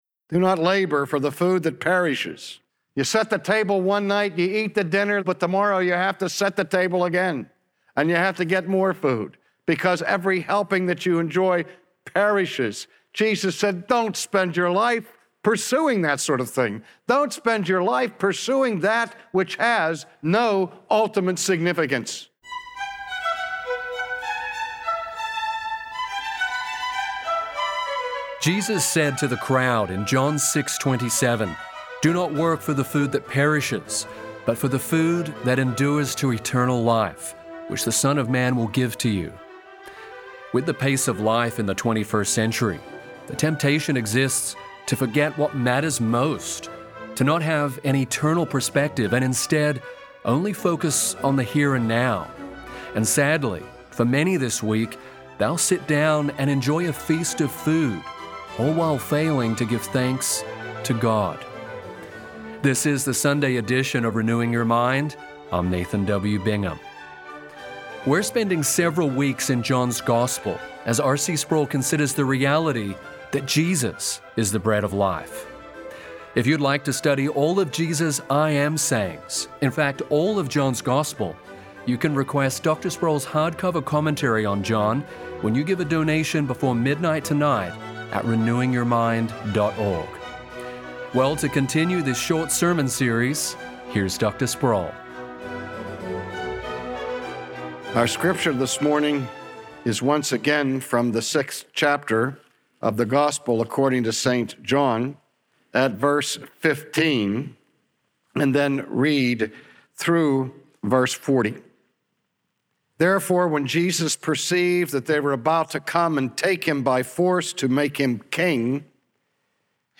Instead, seek the bread from heaven that satisfies forevermore. From his sermon series in the gospel of John, today R.C. Sproul presents Jesus Christ as the One who meets our every need and desire.